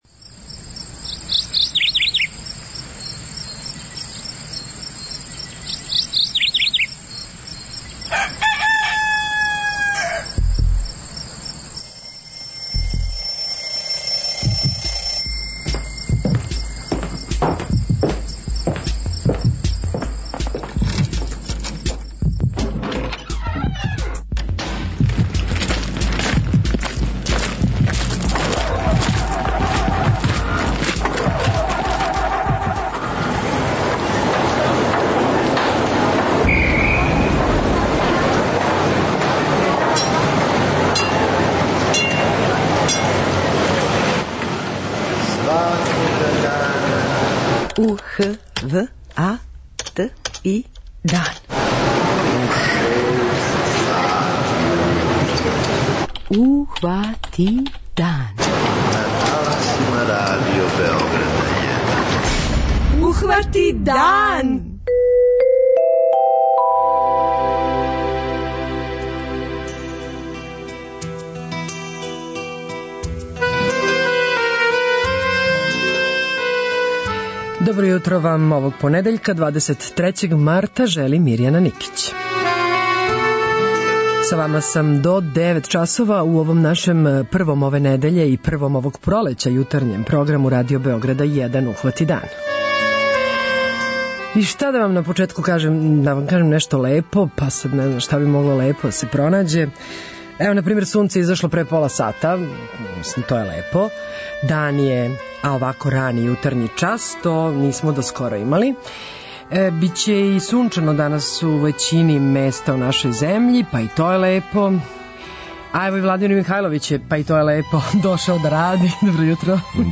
Из садржаја Јутарњег програма издвајамо: